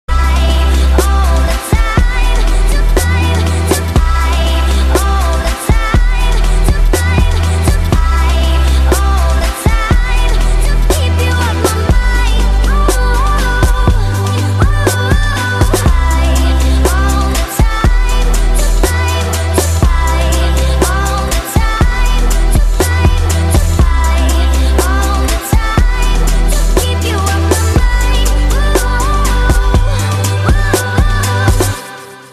M4R铃声, MP3铃声, 欧美歌曲 107 首发日期：2018-05-15 13:46 星期二